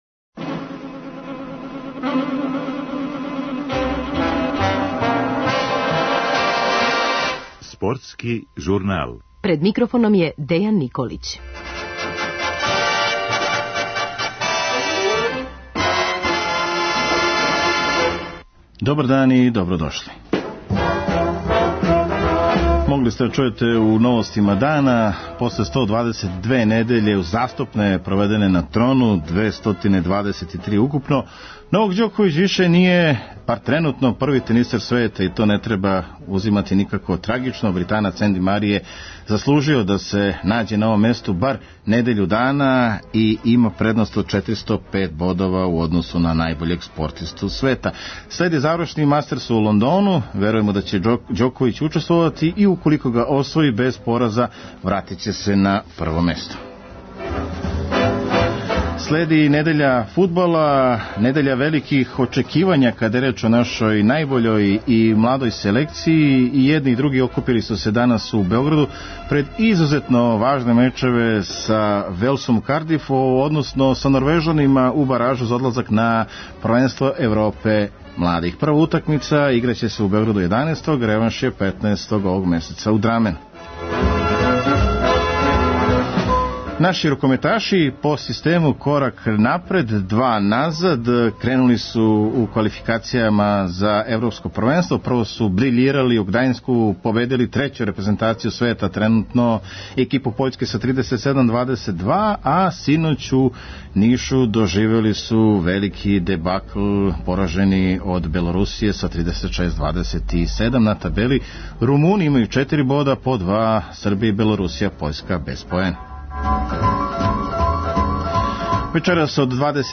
Гoст eмисиje